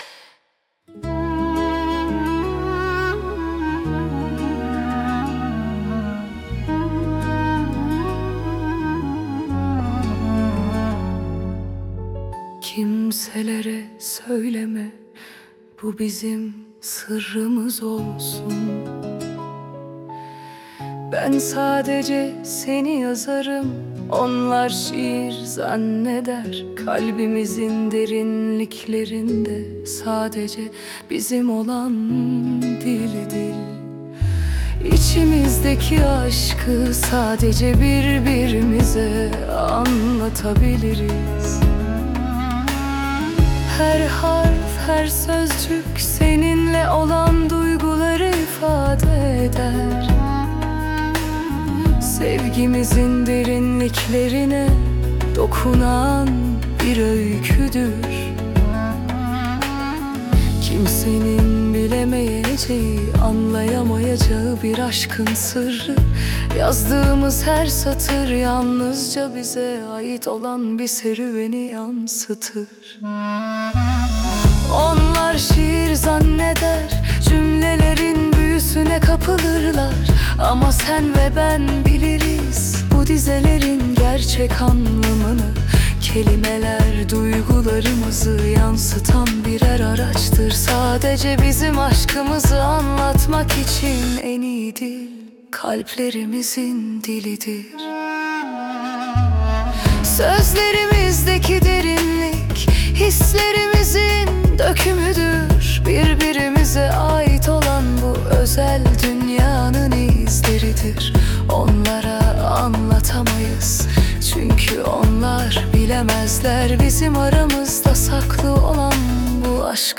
AI ile üretilen 46+ özgün müziği keşfedin
🎤 Vokalli 18.10.2025